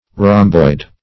Rhomboid \Rhom"boid\ (r[o^]m"boid), a.